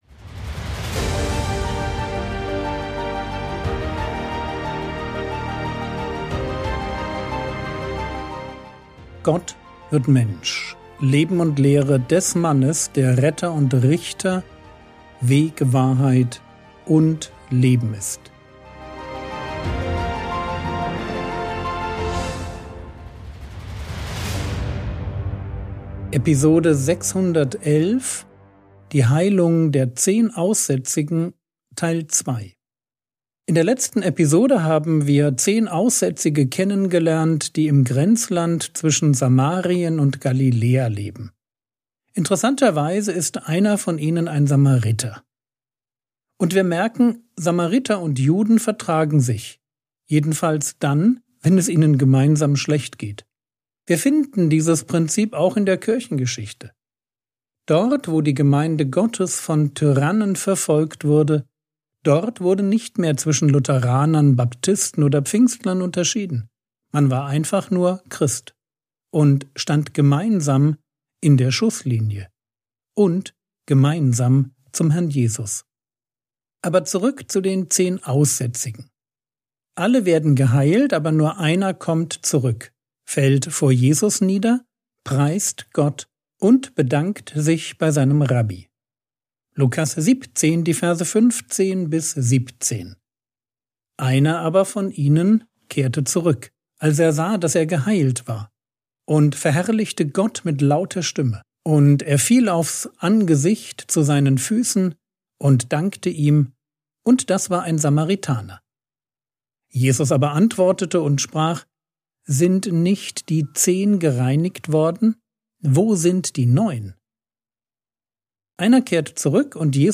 Episode 611 | Jesu Leben und Lehre ~ Frogwords Mini-Predigt Podcast